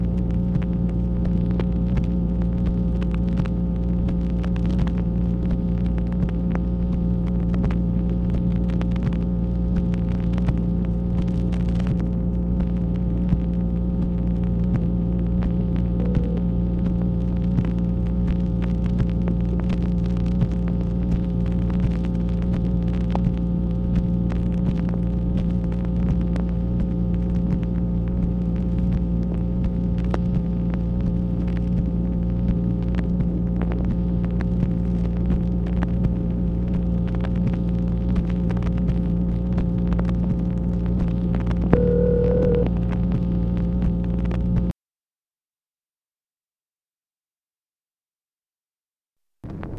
MACHINE NOISE, September 8, 1966
Secret White House Tapes | Lyndon B. Johnson Presidency